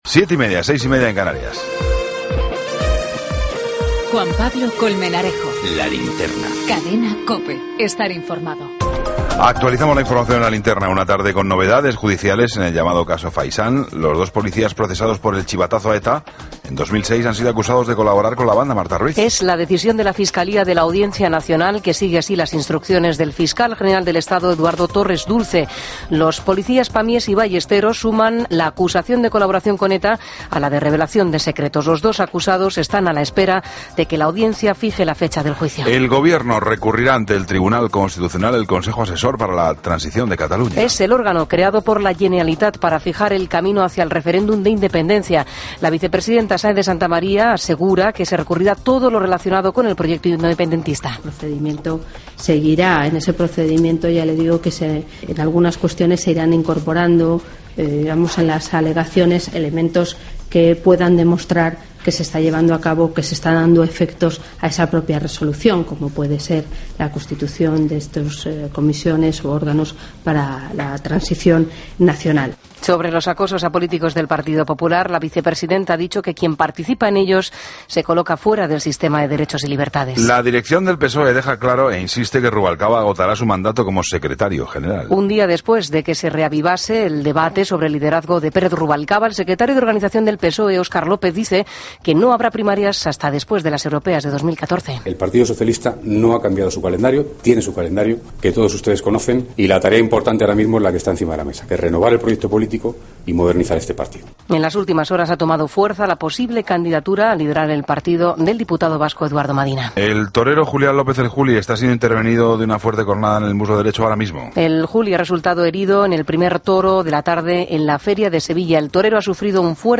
Ronda de corresponsales.
Entrevista a Miguel Zugaza, director del Museo del Prado.